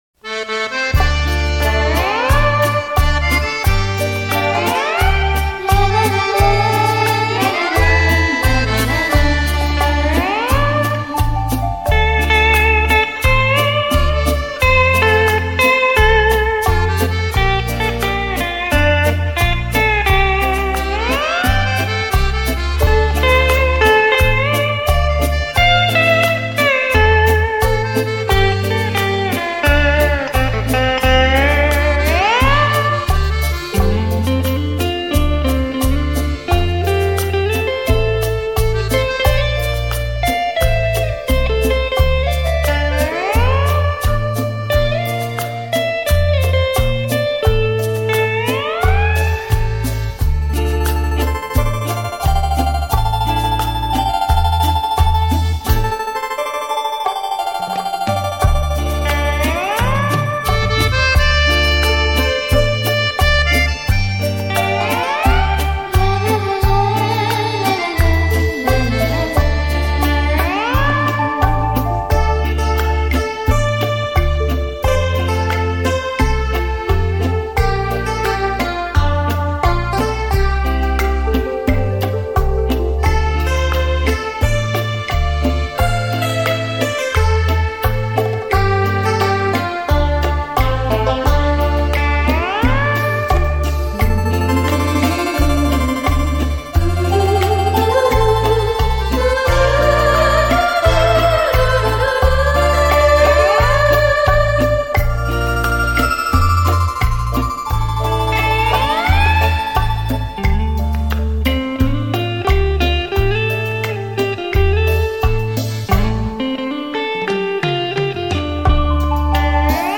专辑格式：DTS-CD-5.1声道
錄音：佳聲錄音室
閒適優雅的音符，完美傳真的音質，興來CD雷射唱片，呈獻在您的耳際
優美動聽的旋律讓人沉醉其中...